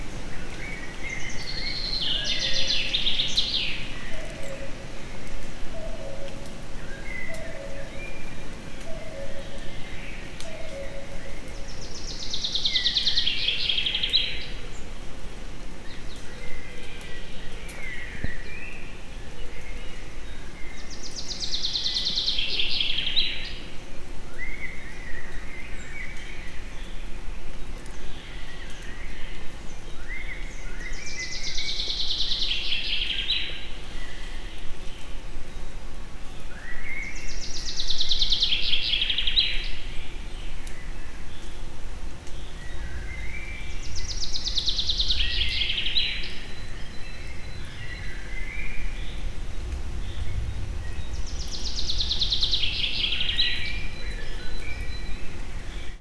erdeipinty_enek_egerturistahaz00.56.WAV